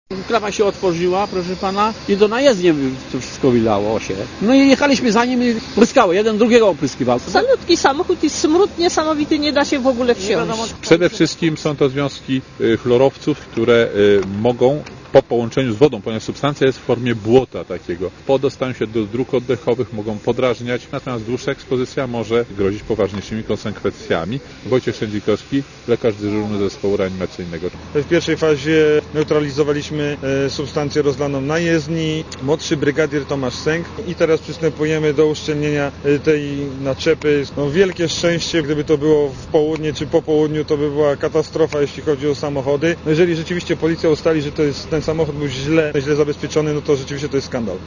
Strażacy i ludzie poszkodowani w wypadku mówili reporterowi Radia Zet, że ciężarówka przewożąca odpady nie była do tego przystosowana.
Posłuchaj, co mówią świadkowie wypadku (186 KB)